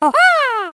One of Diddy Kong's voice clips in Mario Kart: Double Dash!!